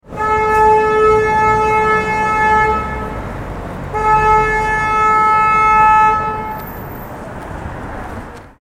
Honking Car Horn Sound Effect Free Download
Honking Car Horn